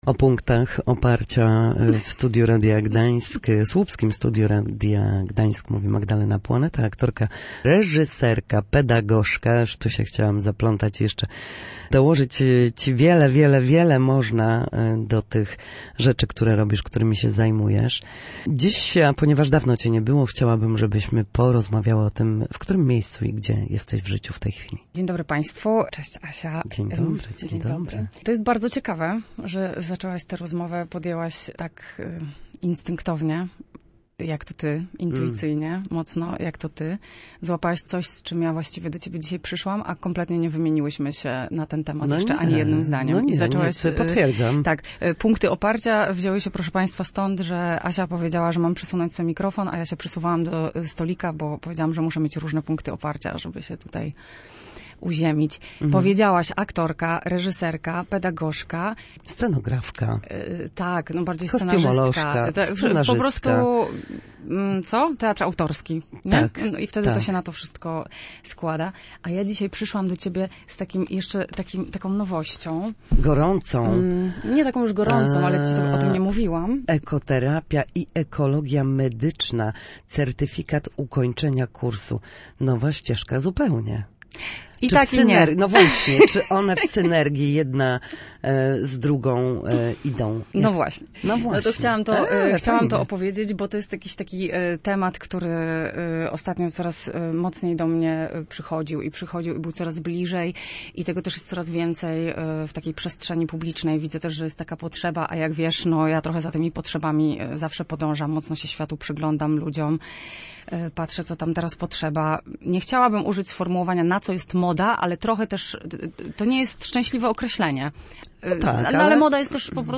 Studio Słupsk Radia Gdańsk